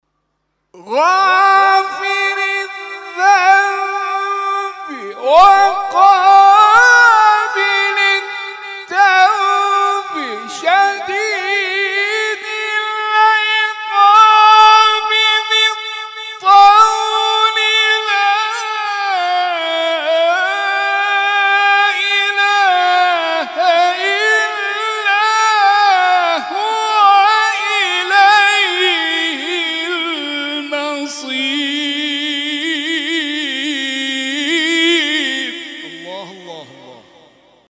محفل انس با قرآن در آستان عبدالعظیم(ع)+ صوت